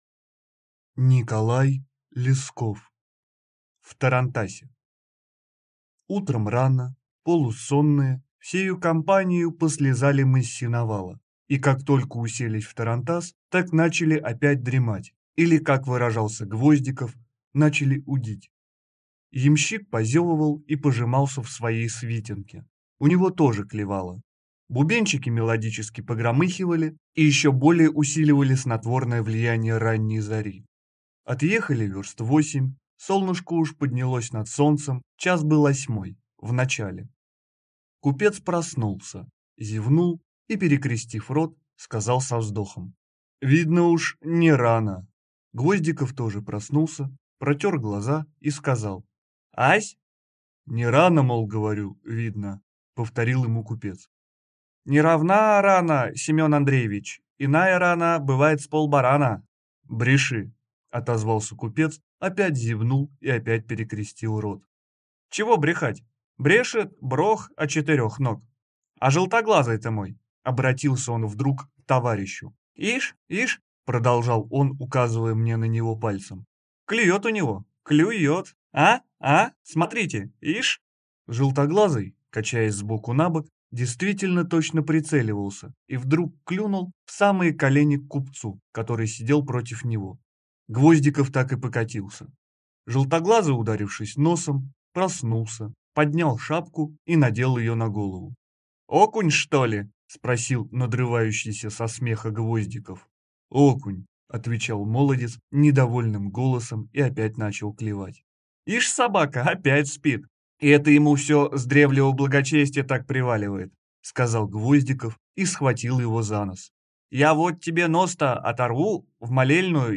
Аудиокнига В тарантасе | Библиотека аудиокниг